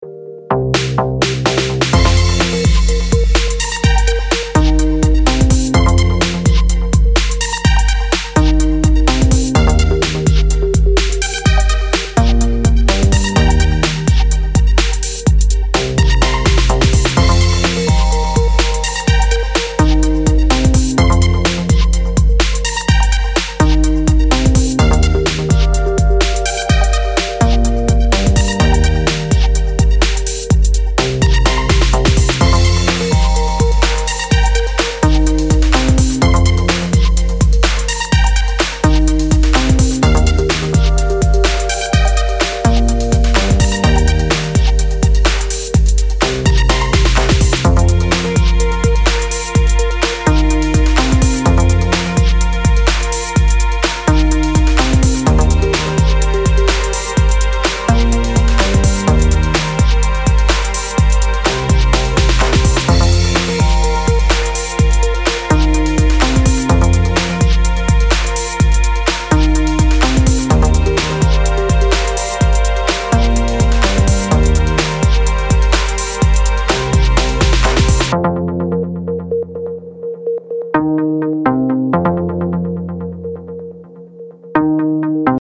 as part of a five-track EP of deep house and electro